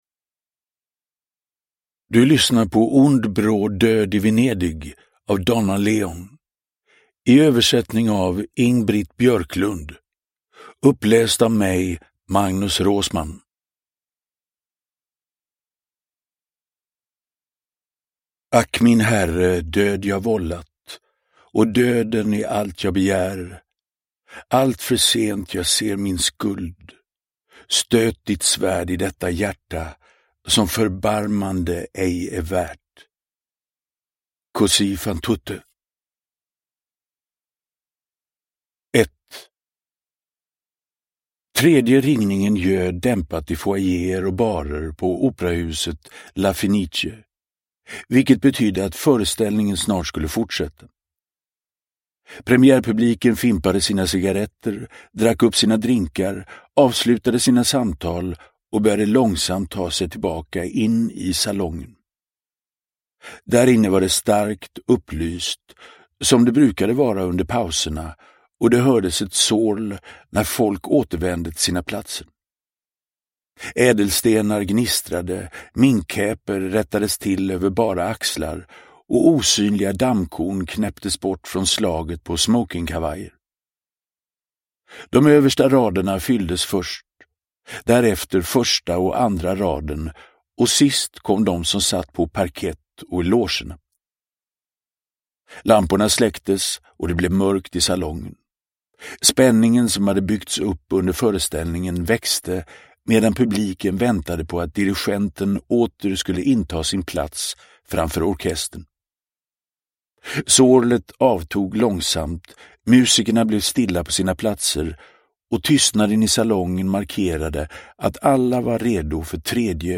Ond bråd död i Venedig – Ljudbok – Laddas ner
Uppläsare: Magnus Roosmann